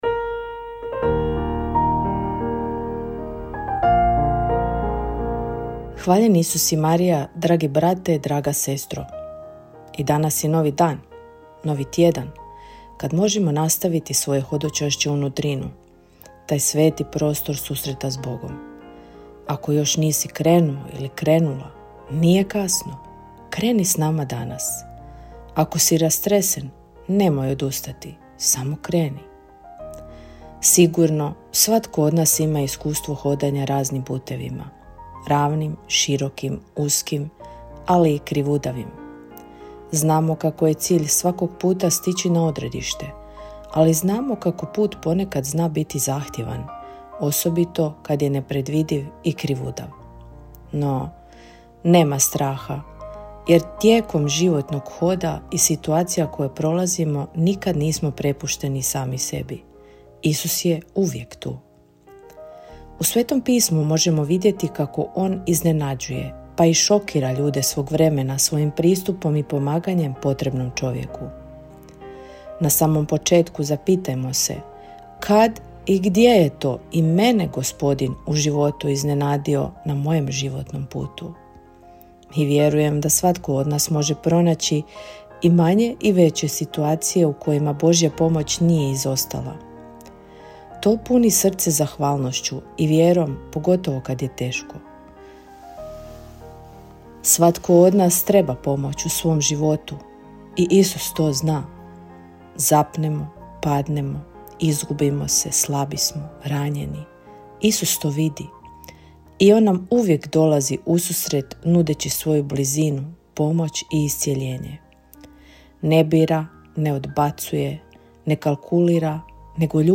Kratku emisiju ‘Duhovni poticaj – Živo vrelo’ slušatelji Radiopostaje Mir Međugorje mogu čuti od ponedjeljka do subote u 3 sata, te u 7:10. Emisije priređuju svećenici i časne sestre u tjednim ciklusima.